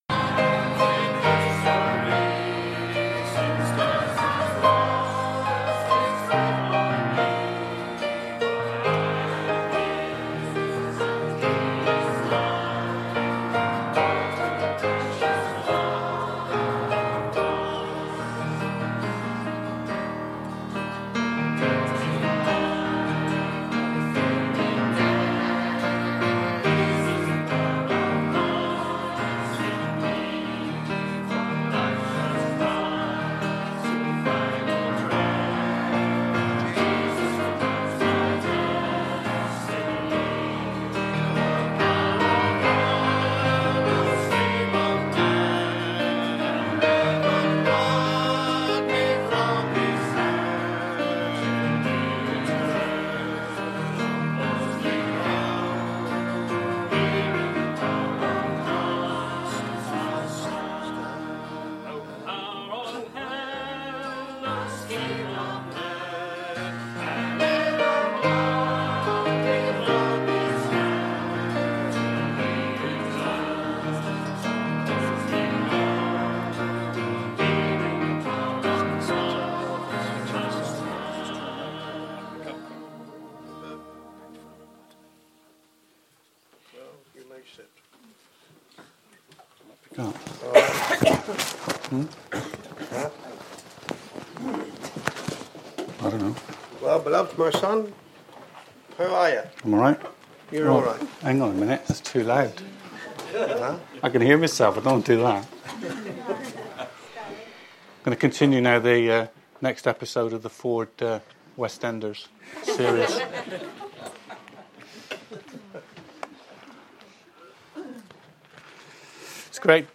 Series: Bible Characters | Preached at Rora Christian Fellowship Devon UK